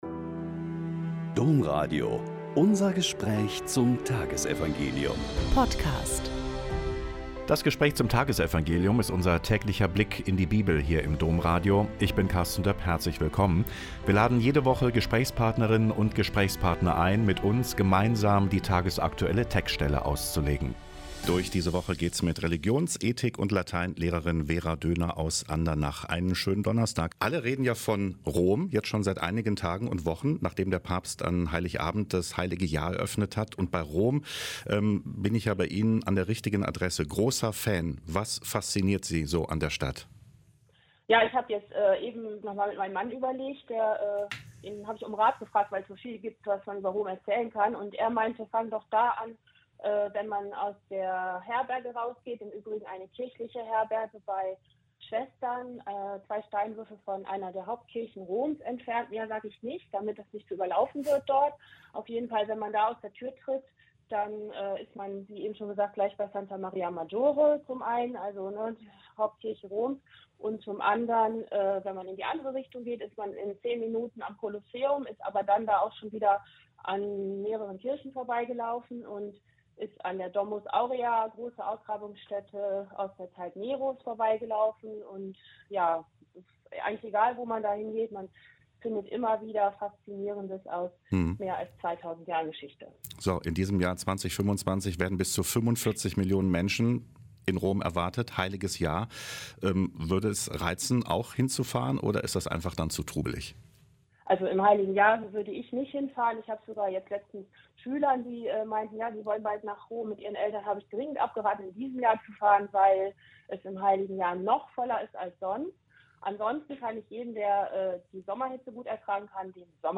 Mk 1,40-45 - Gespräch